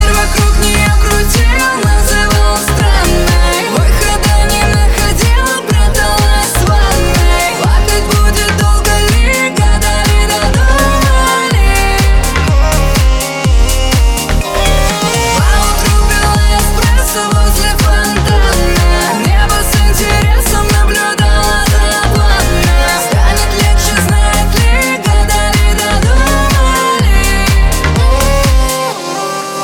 громкие
Club House